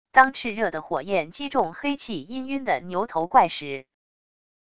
当炽热的火焰击中黑气氤氲的牛头怪时wav音频